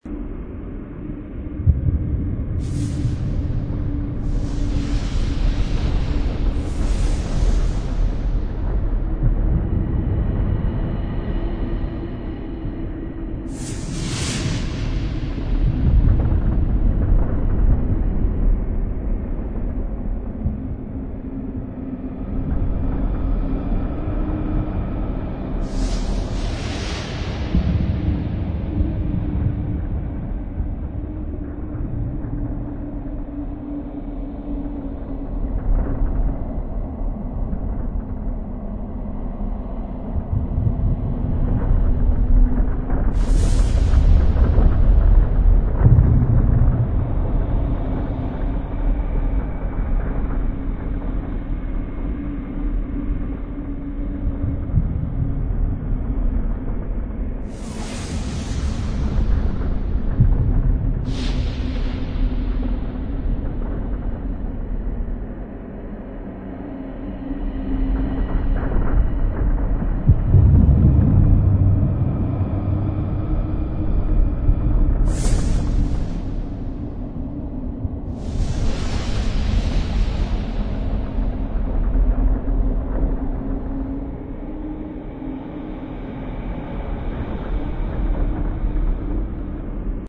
zone_field_asteroid_lava.wav